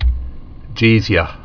(jēzyə)